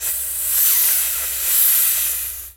snake_hiss_06.wav